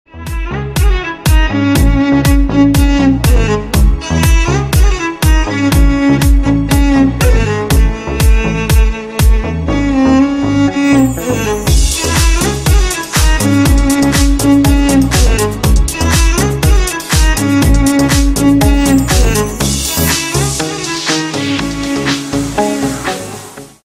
Рингтоны Без Слов
Танцевальные Рингтоны